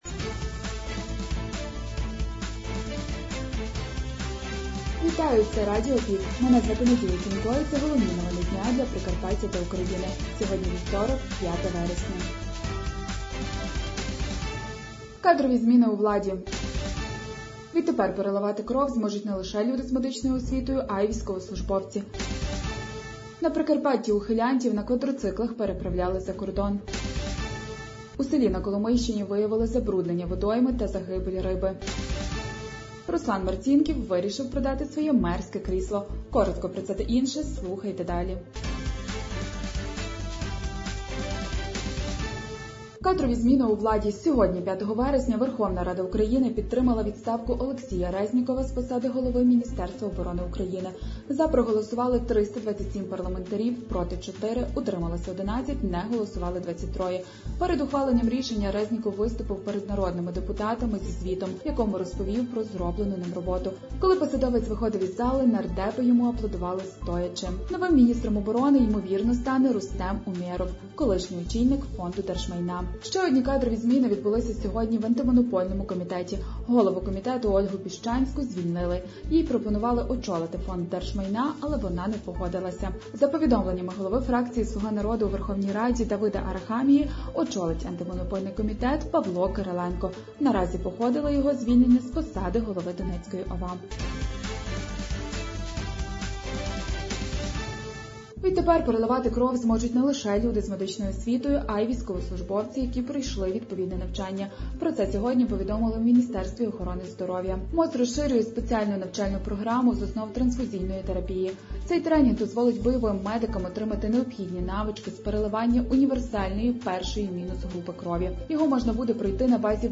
Пропонуємо вам актуальне за день – у радіоформаті.